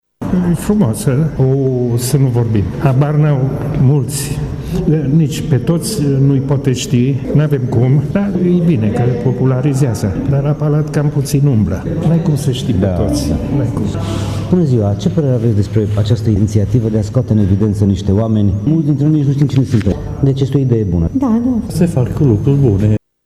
Tg.mureșenii apreciază ideea, chiar dacă unele din personalitățile expuse nu sunt foarte cunoscute marelui public: